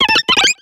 Cri de Ptiravi dans Pokémon X et Y.